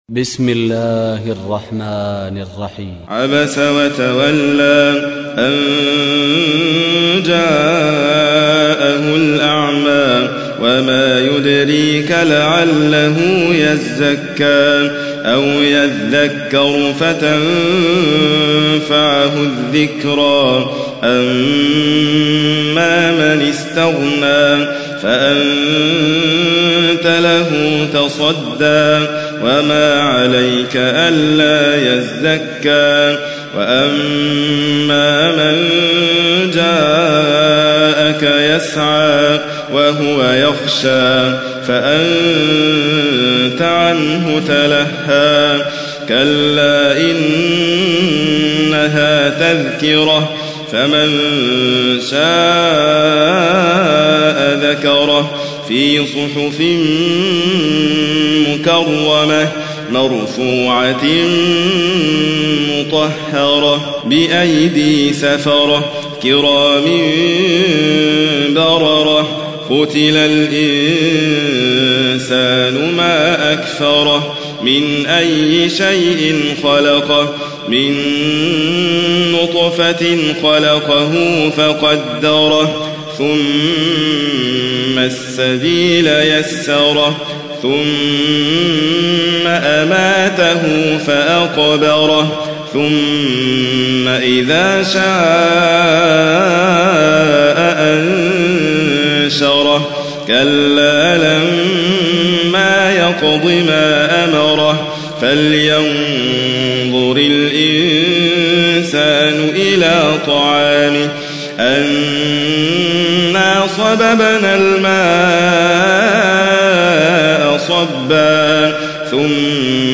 تحميل : 80. سورة عبس / القارئ حاتم فريد الواعر / القرآن الكريم / موقع يا حسين